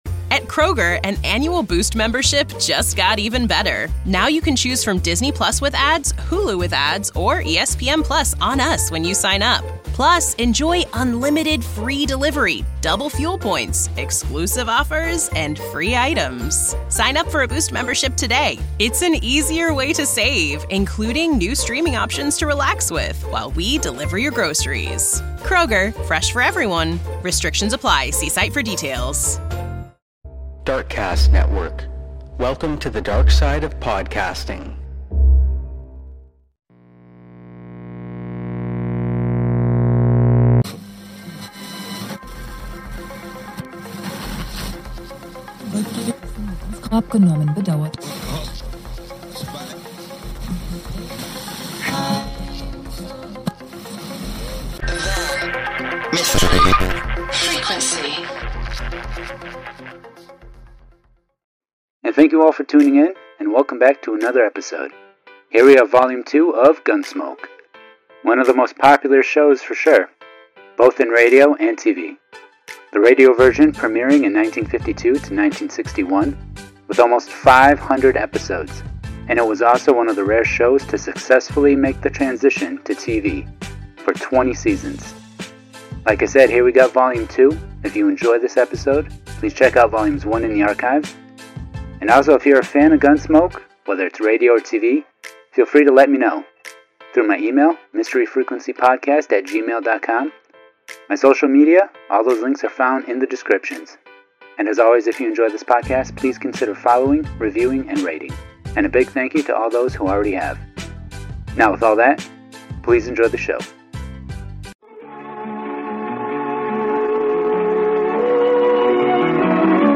1 .455 Old Time Radio Show | Gunsmoke [Vol 2] Classic Western Audio Drama 52:25